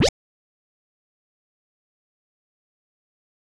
kits/Southside/FX/Slip.wav at ts
Slip.wav